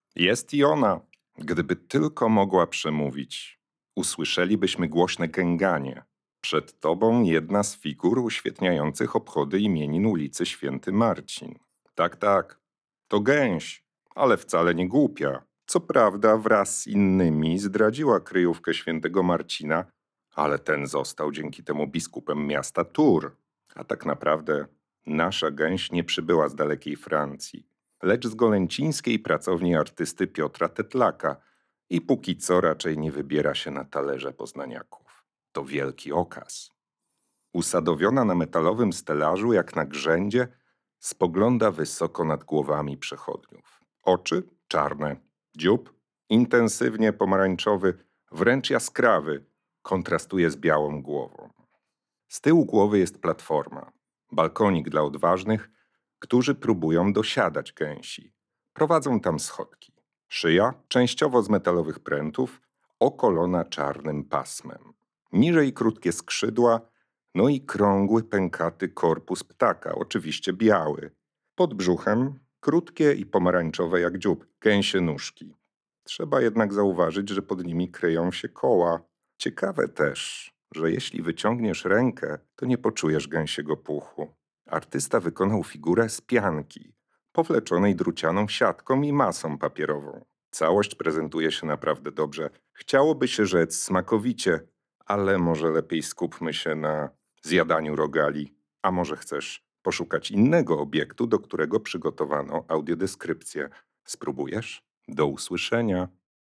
Gęsi – audiodeskrypcja